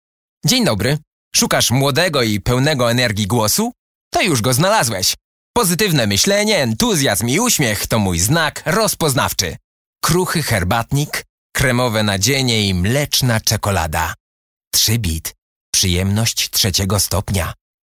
Male 30-50 lat
Nagranie lektorskie